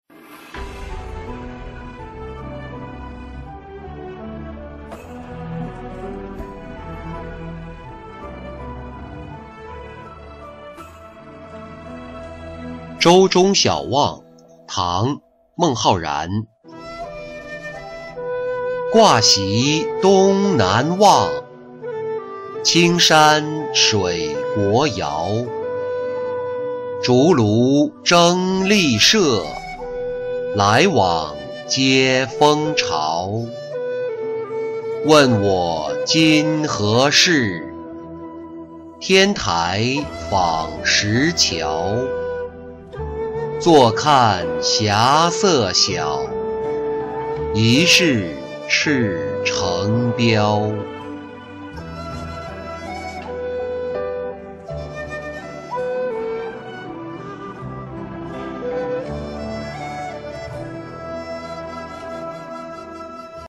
舟中晓望-音频朗读